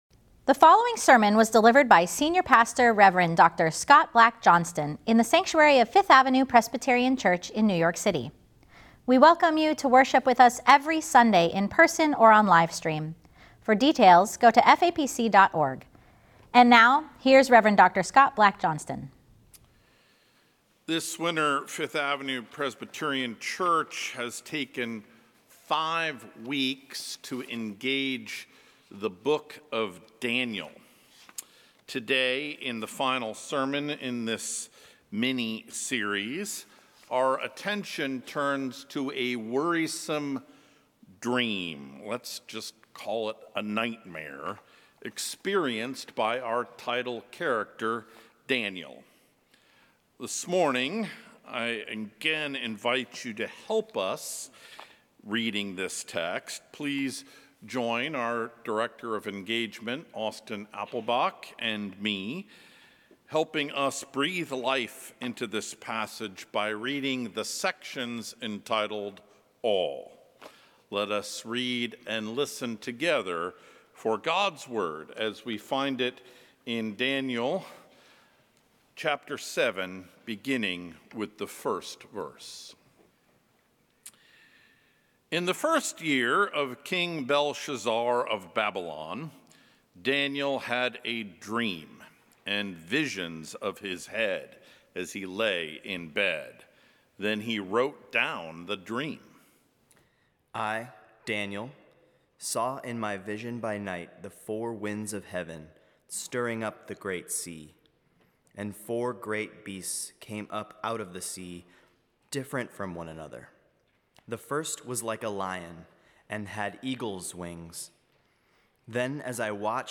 Sermon: “God vs. Zilla” Scripture: Daniel 7:1-14 Download sermon audio Order of Worship I n the first year of King Belshazzar of Babylon, Daniel had a dream and visions of his head as he lay in bed.
Sermon_podcast_2-23-25_.mp3